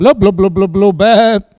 Cri pour appeler les brebis ( prononcer le cri )